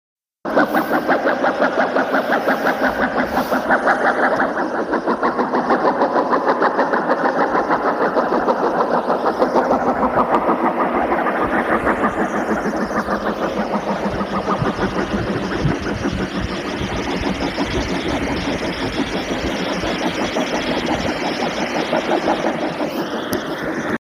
Ailen ghost Sounds ( trevor henderson )